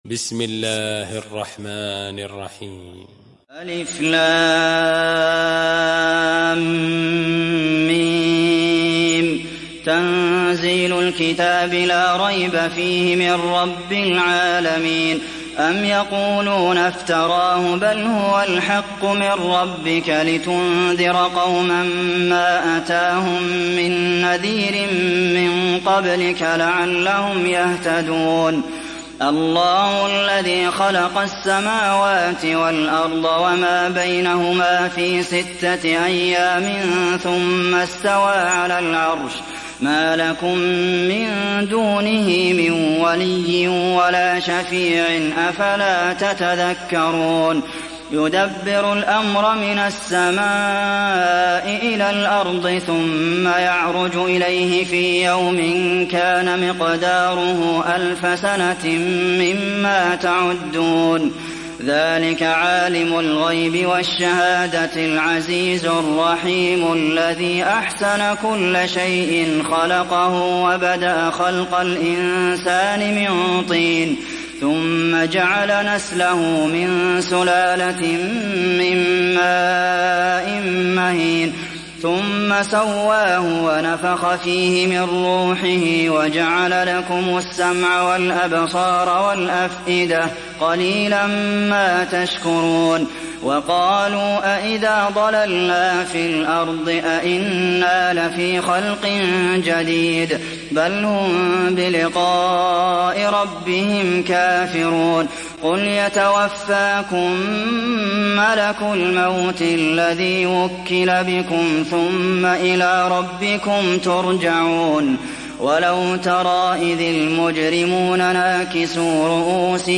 Surat As Sajdah Download mp3 Abdulmohsen Al Qasim Riwayat Hafs dari Asim, Download Quran dan mendengarkan mp3 tautan langsung penuh